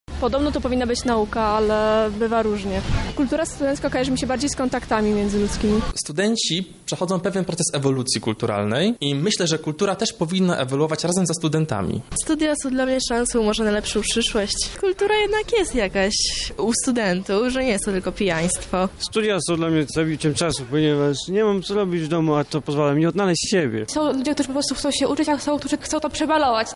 Kultura studencka kiedyś kojarzyła się z wolnością i buntem przeciwko przyjętym regułom. Zapytaliśmy lubelskich żaków czym dla nich są studia i kultura akademicka: